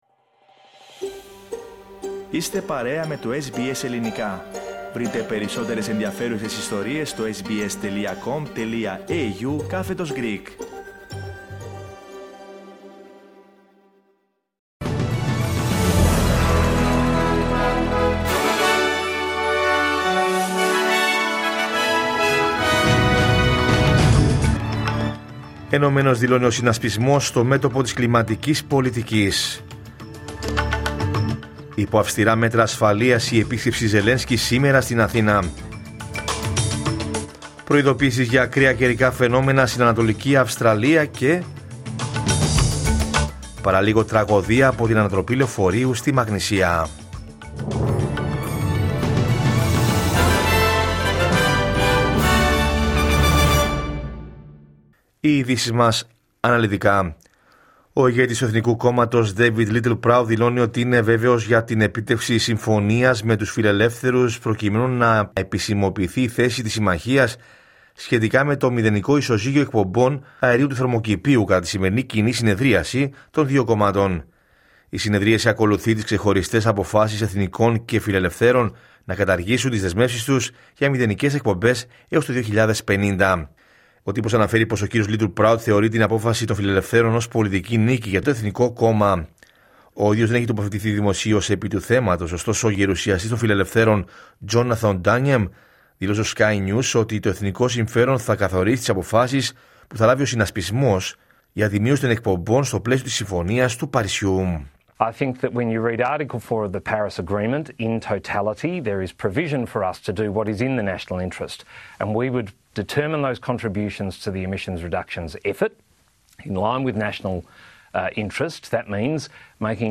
Ειδήσεις: Κυριακή 16 Νοεμβρίου 2025